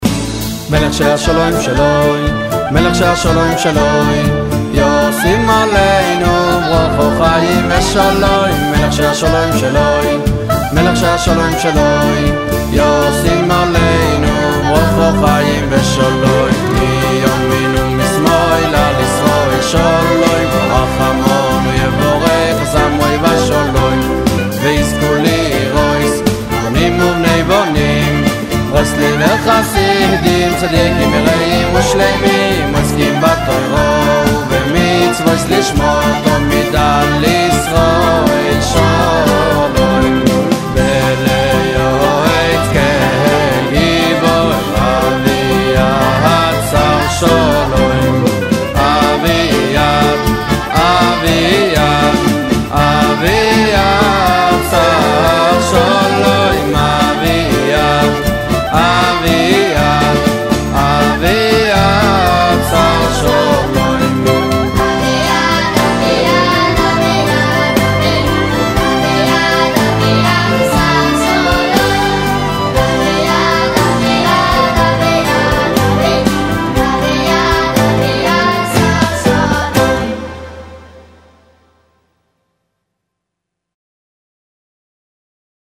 הניגון ששרים בסעודה שלישית על הבית האחרון מהפיוט